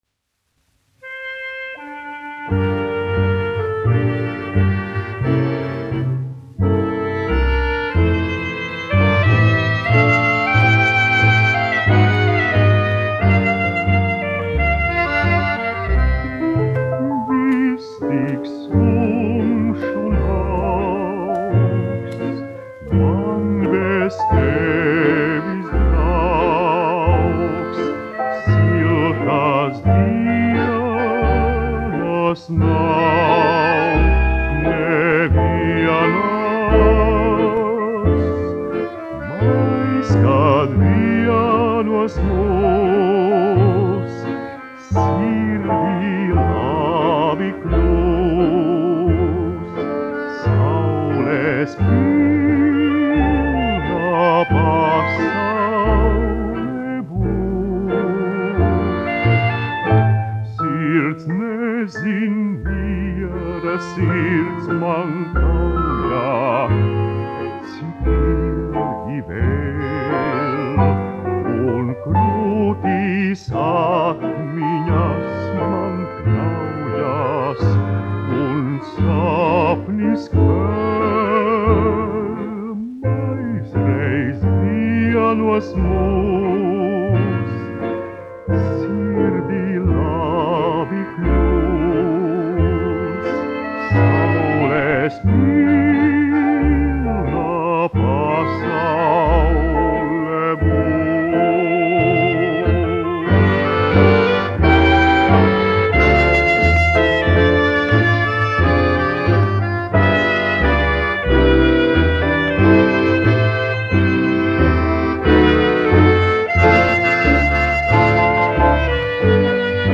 dziedātājs
1 skpl. : analogs, 78 apgr/min, mono ; 25 cm
Populārā mūzika
Fokstroti
Skaņuplate